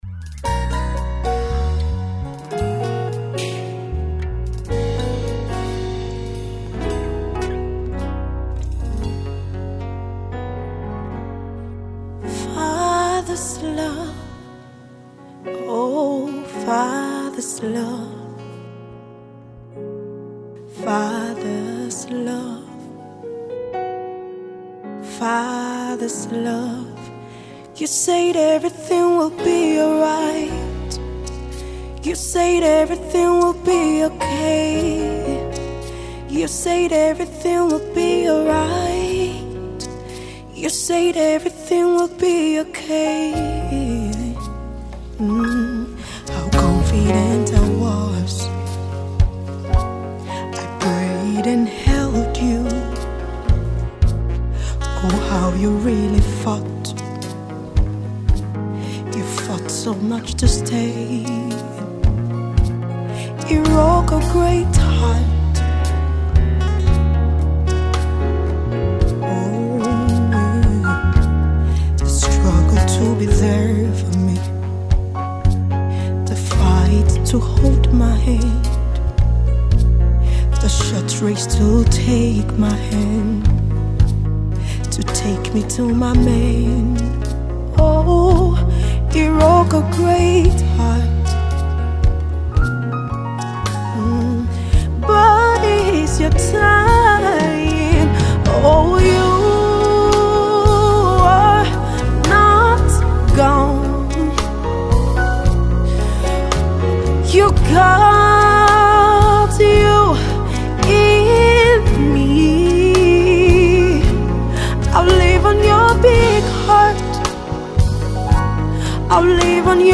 contemporary gospel artiste of the well known classic